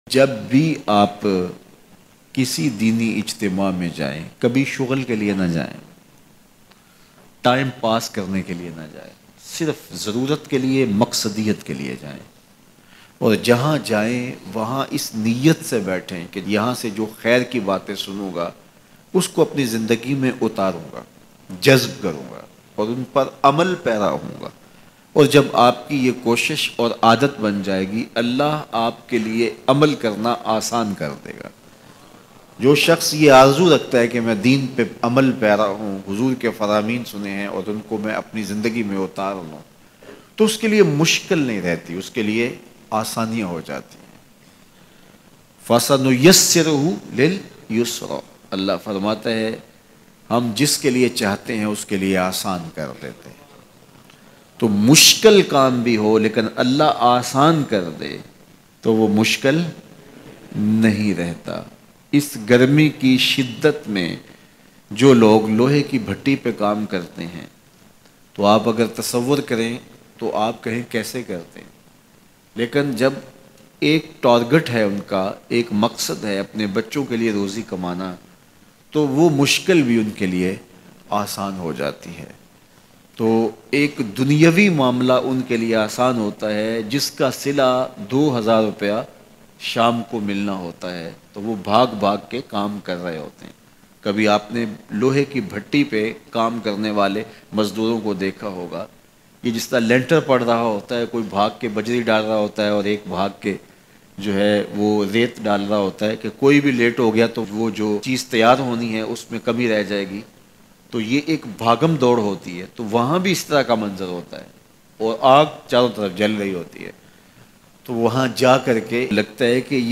Ap Cricket k Shoq se Aashna he nai hain Bayan